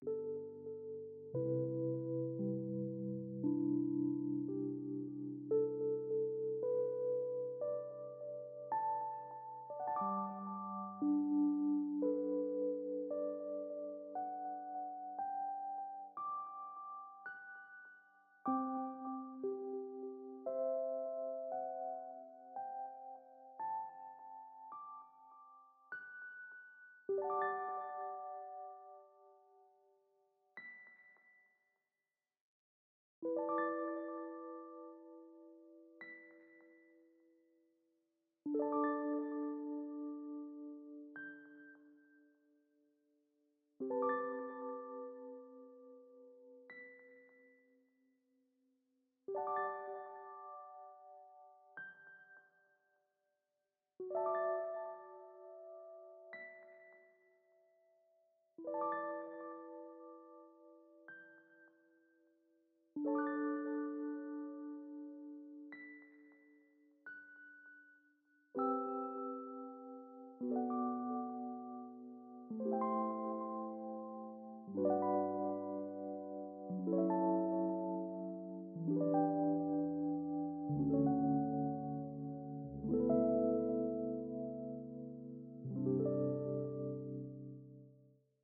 【1人声劇】 寝落ちしてる君へ